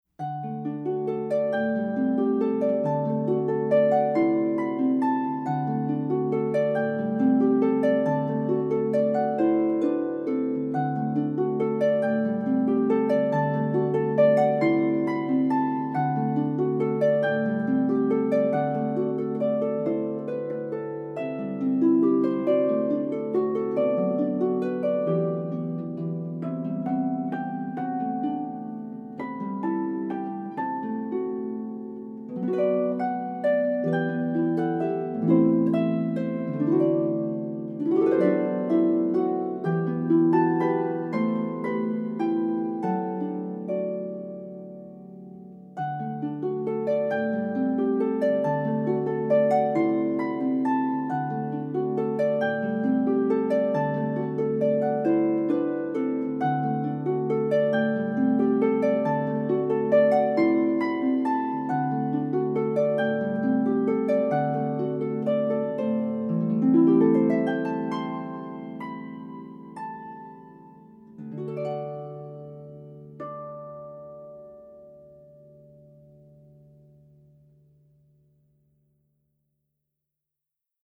for solo lever or pedal harp
SO light and joyous.